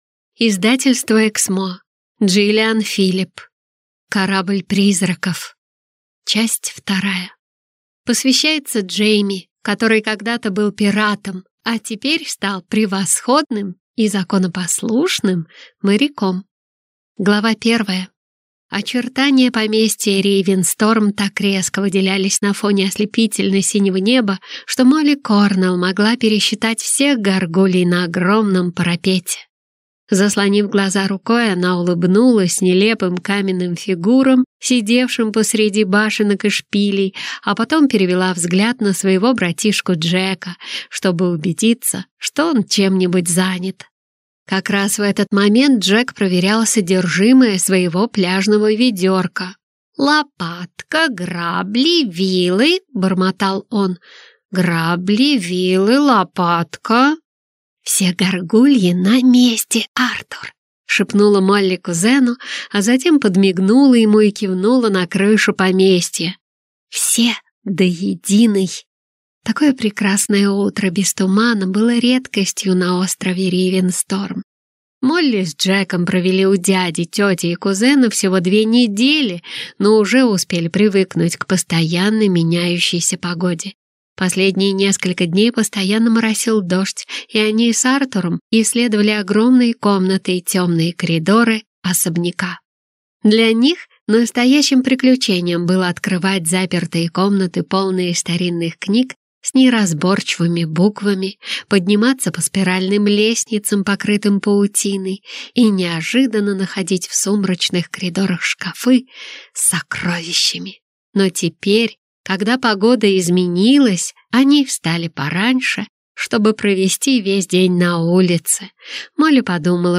Аудиокнига Корабль призраков | Библиотека аудиокниг